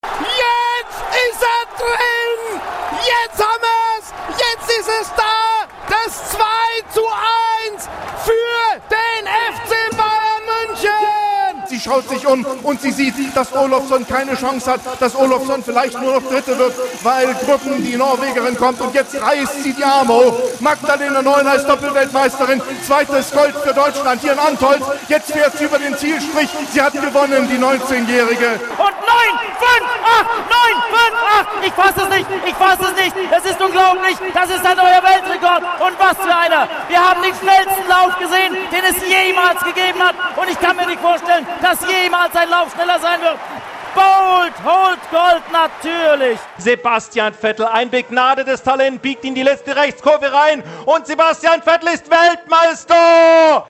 SPORT
Spannende Stadionreportagen, Siegestaumel, Verliererenttäuschung.
sport.mp3